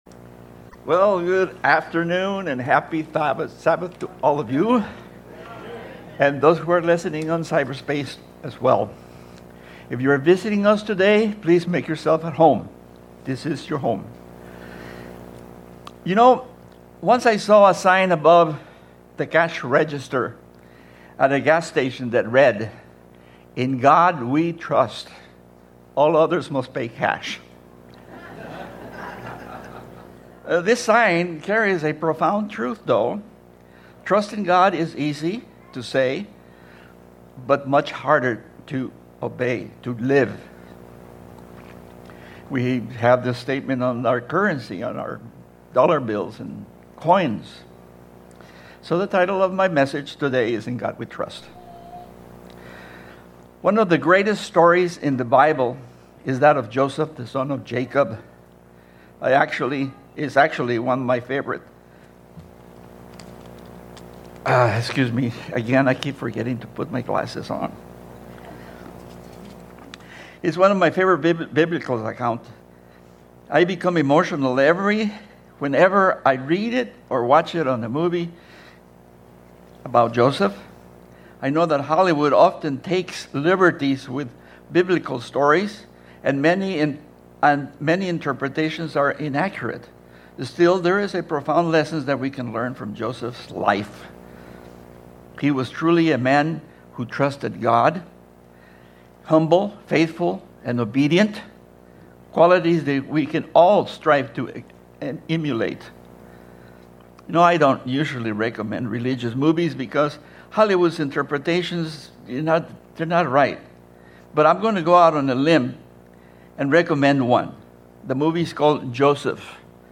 Given in Orange County, CA